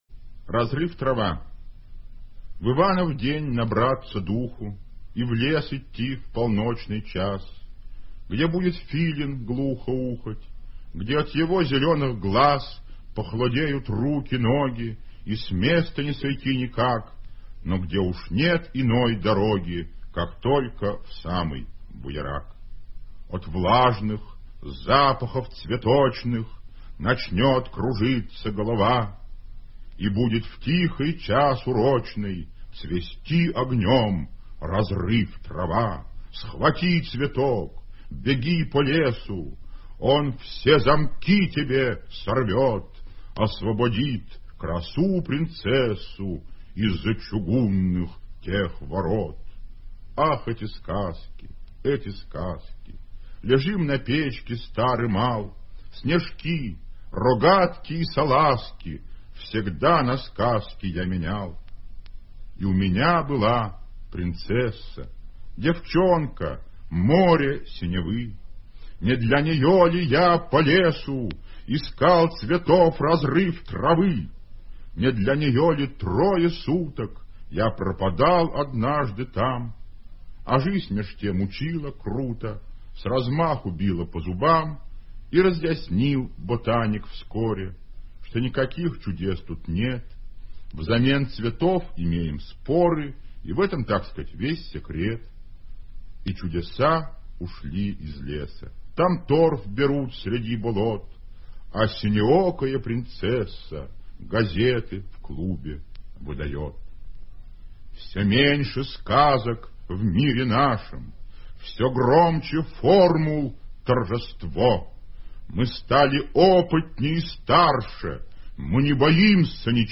1. «Владимир Солоухин – Разрыв-трава (читает автор)» /
vladimir-solouhin-razryv-trava-chitaet-avtor